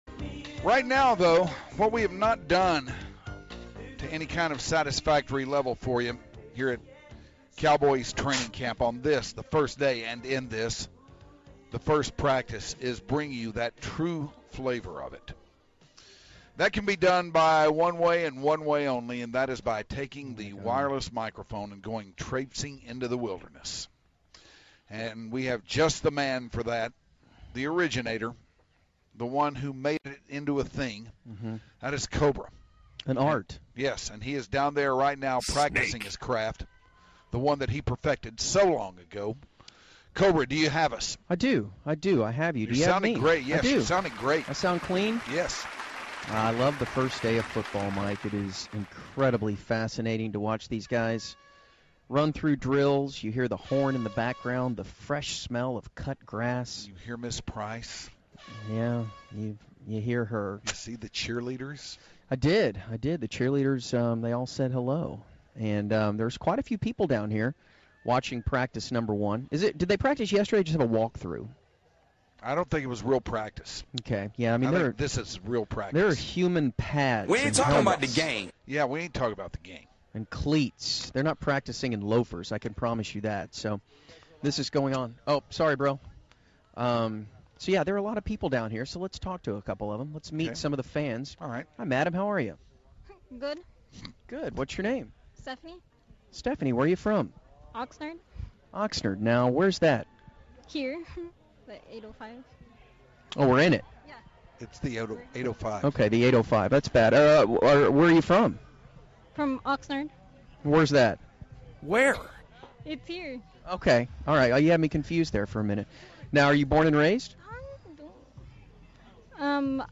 goes wireless for the first time at training camp